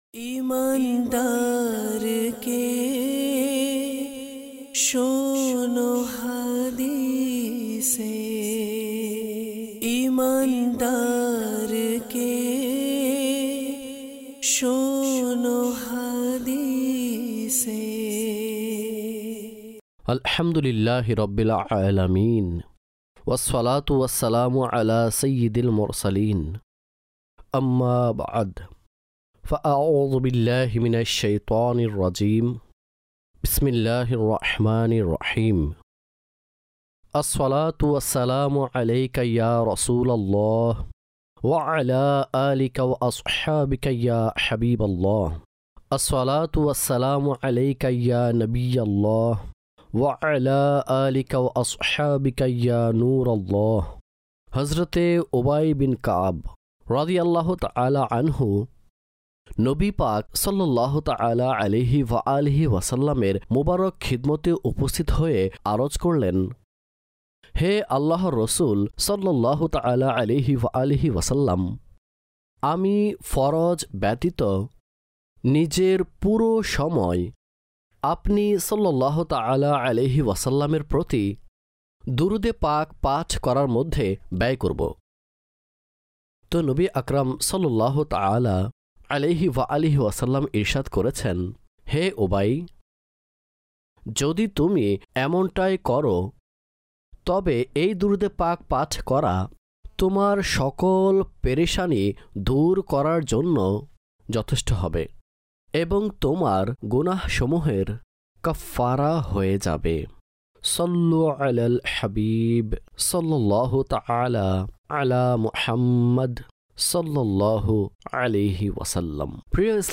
দরসে হাদীস শরীফ (বাংলায় ডাবিংকৃত) EP# 9